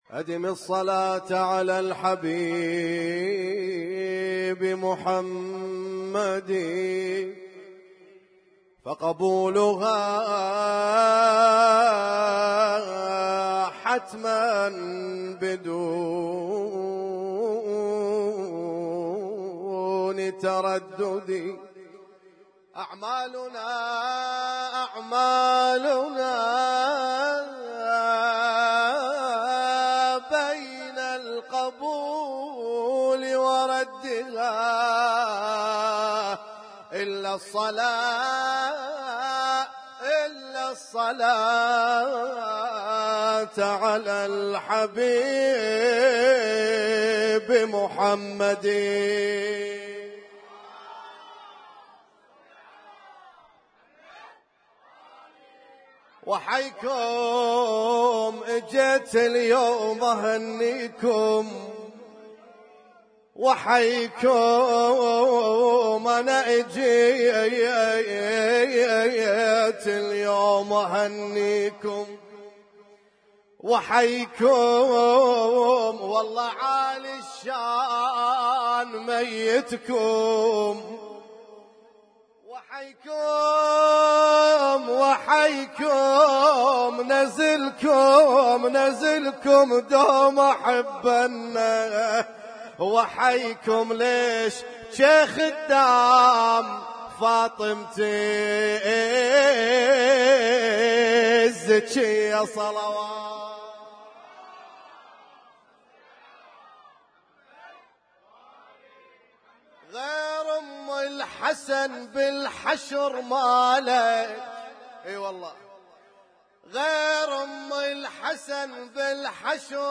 Husainyt Alnoor Rumaithiya Kuwait
اسم التصنيف: المـكتبة الصــوتيه >> المواليد >> المواليد 1445